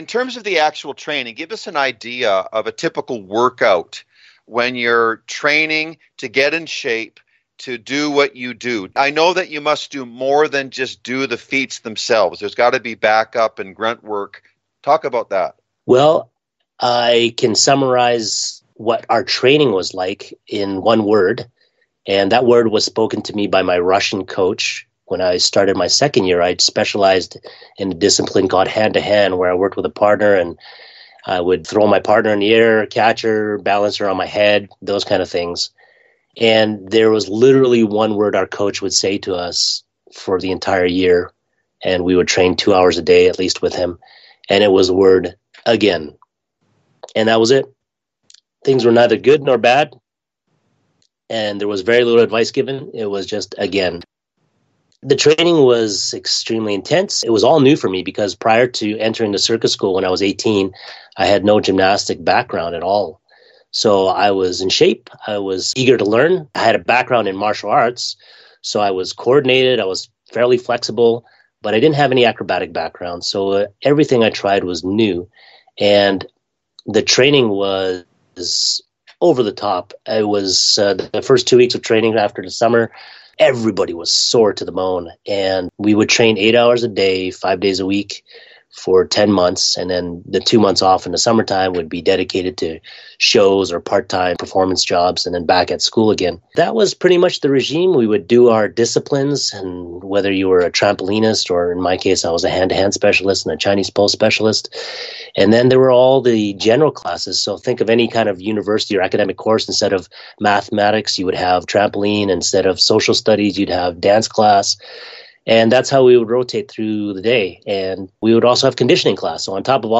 Special Guest Interview